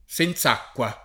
[ S en Z# kk U a ]